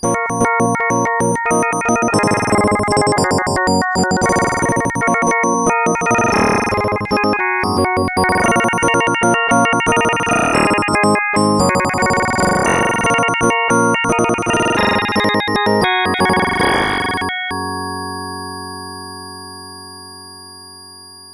Synth: modulator